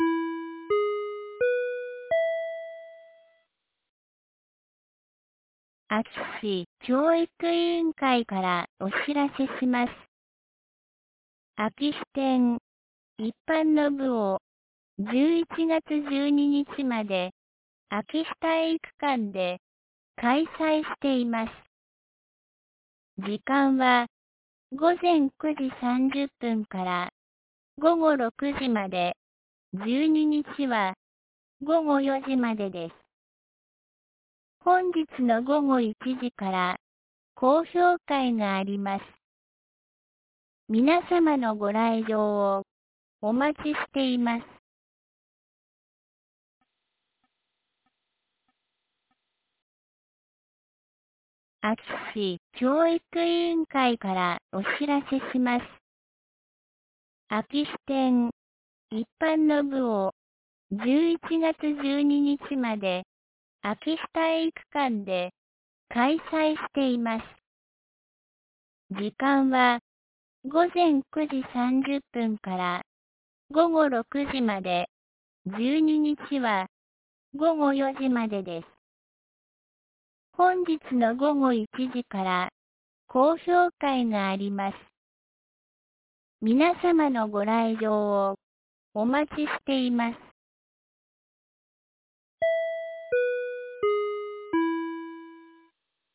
2023年11月05日 12時11分に、安芸市より全地区へ放送がありました。